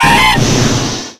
CROCONAW.ogg